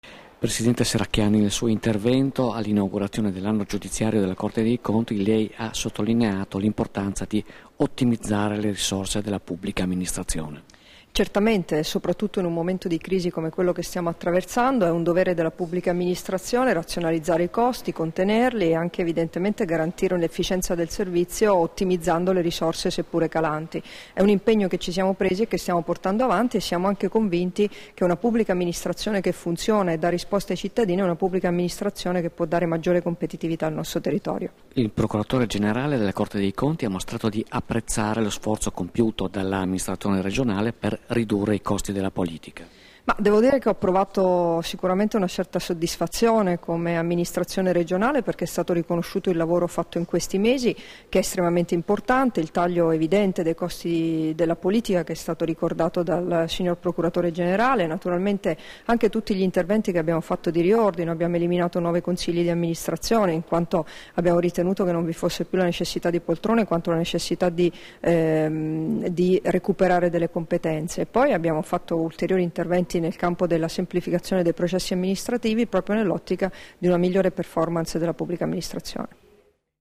Dichiarazioni di Debora Serracchiani (Formato MP3) rilasciate a margine dell'inaugurazione dell'Anno giudiziario 2014 della Sezione giurisdizionale della Corte dei Conti per il Friuli Venezia Giulia, a Trieste il 28 febbraio 2014 [1278KB]